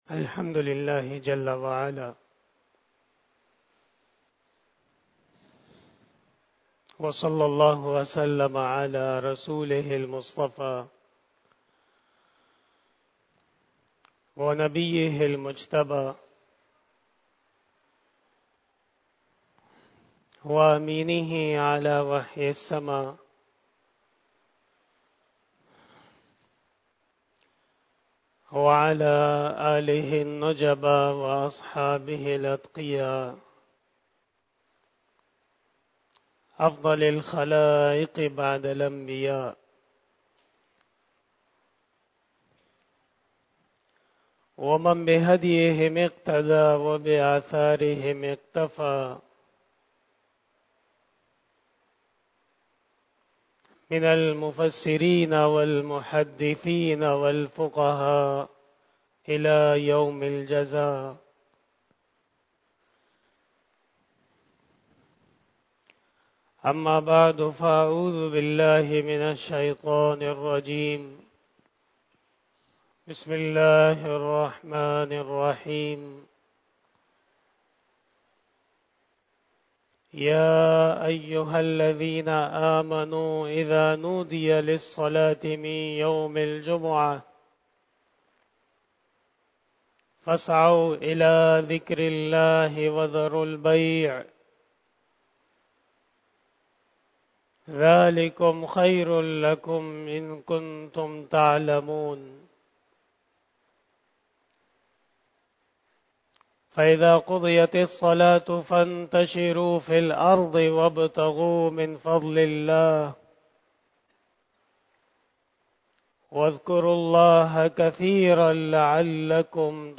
40 BAYAN E JUMA TUL MUBARAK 14 October 2022 (17 Rabi ul Awwal 1444H)